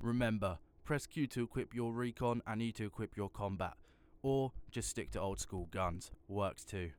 Voice Lines
remember press Q to equip your recon and E to equip your combat.wav